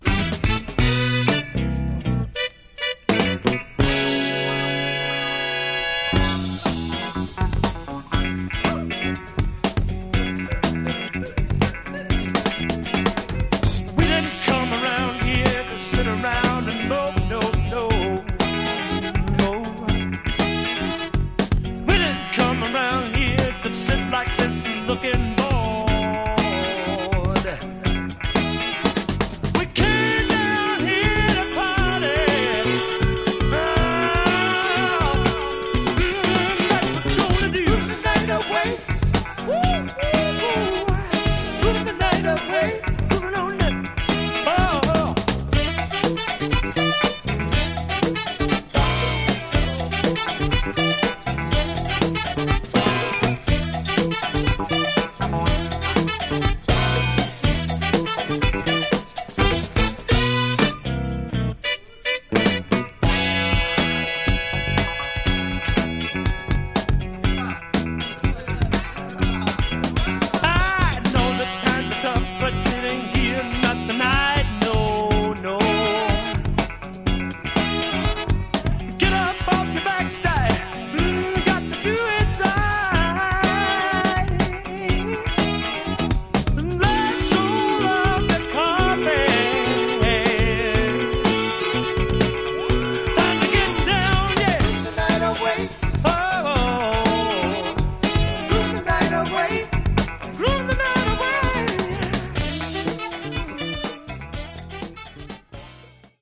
Genre: Funky Soul
but also for the frenetic Funk workout on the flip